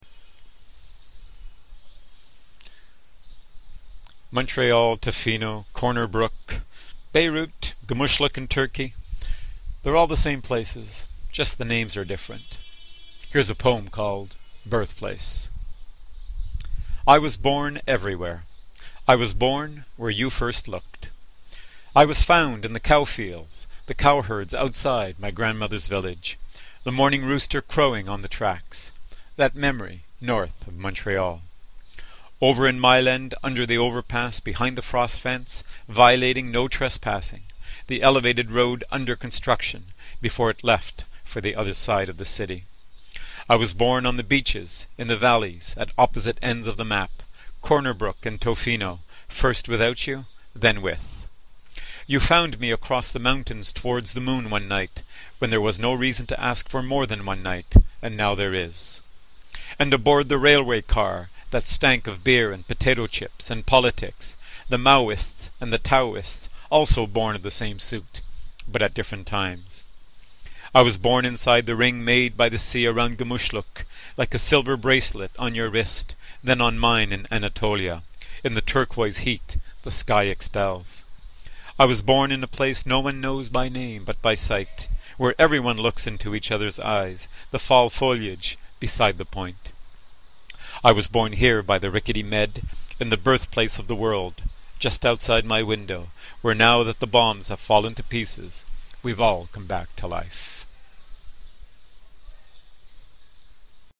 reads Birthplace from Alien, Correspondent (with introduction)